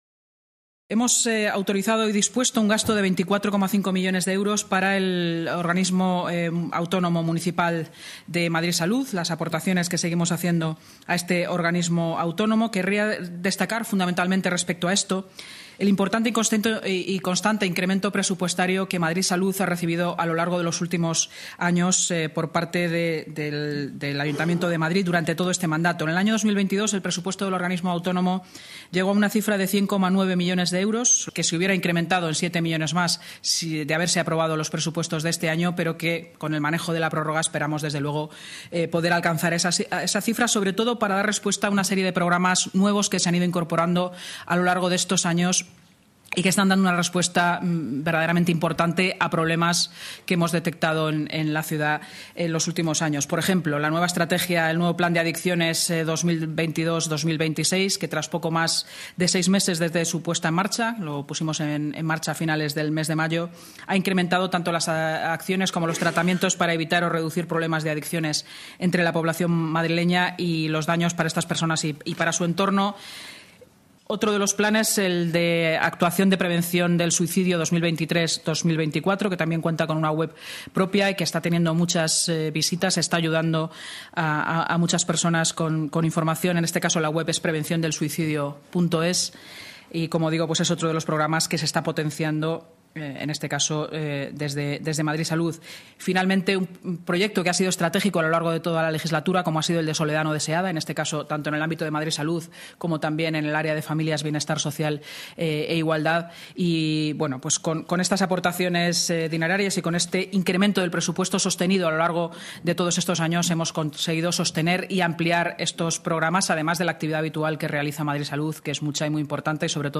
Nueva ventana:Declaraciones de la portavoz municipal, Inmaculada Sanz